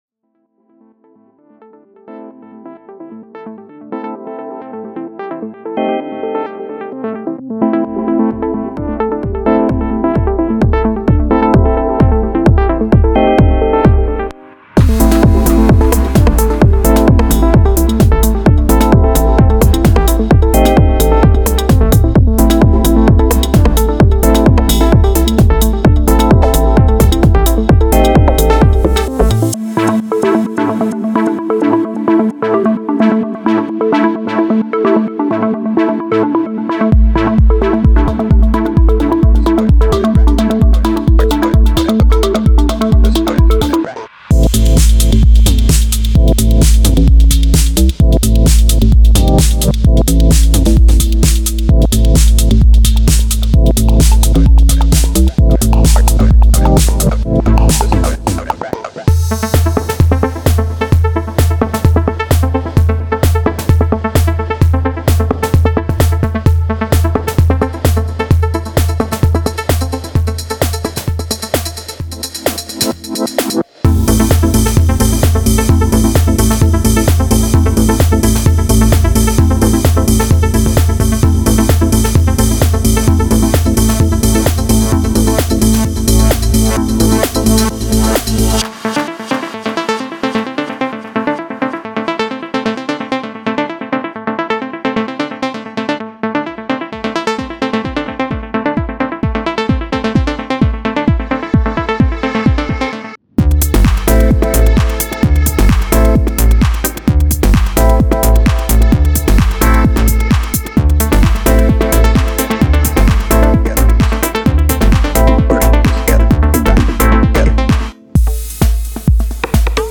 Genre:Minimal Techno
クリーンで表現力豊か、そしてミックスを圧迫することなく深みとキャラクターを加えるよう設計されています。
デモサウンドはコチラ↓